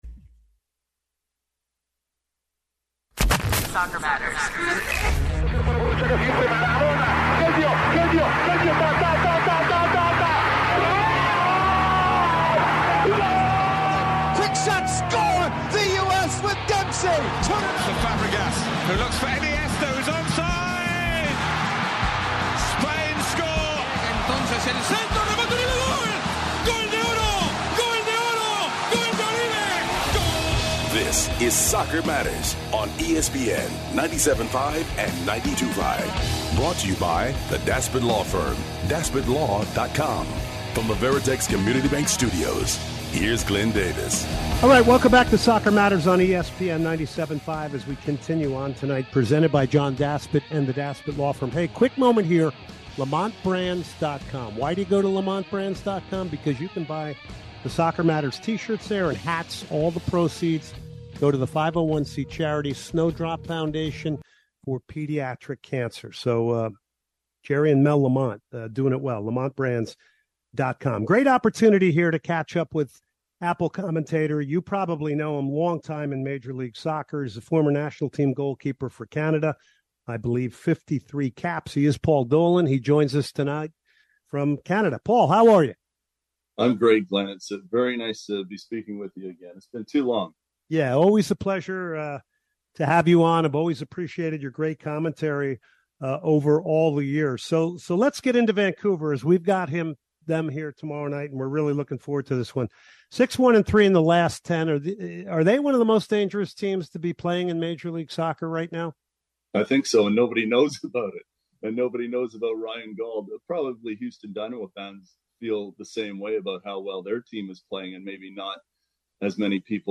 two interviews